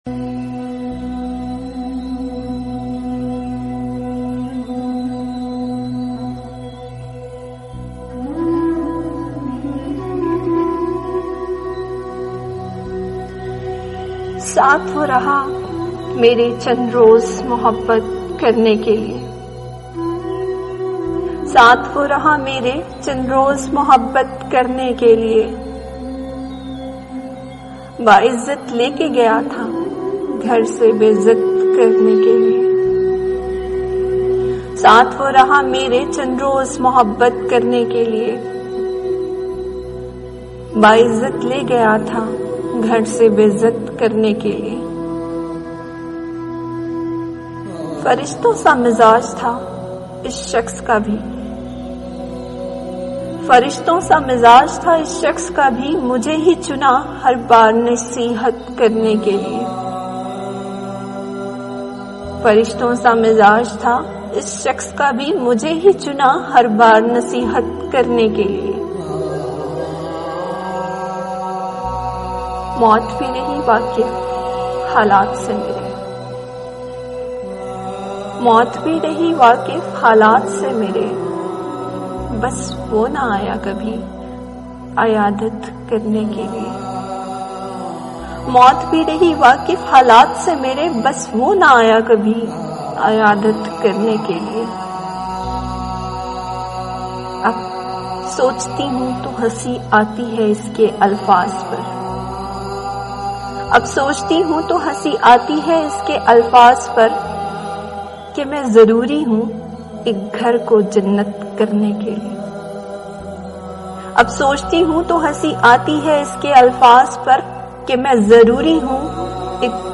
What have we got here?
Parveen Shakir, Audiobooks